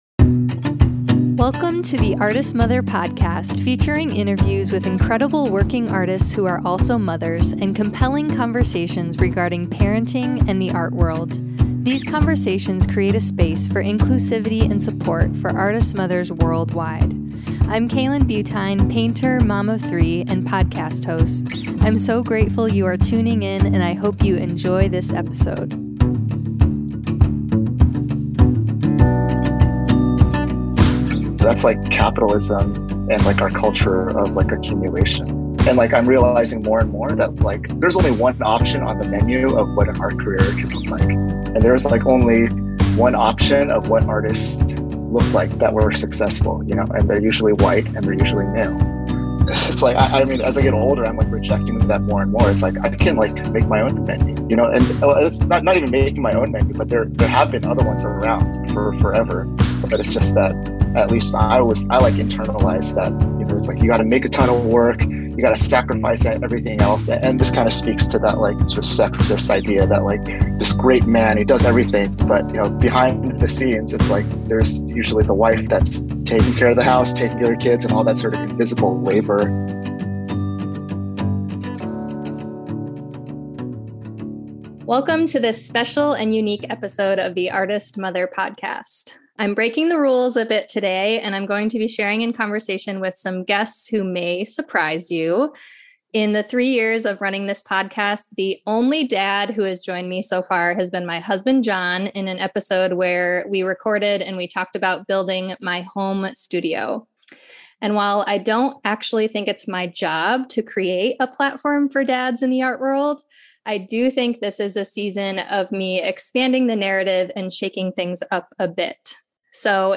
Today I’m chatting with three fabulous Artist DADS.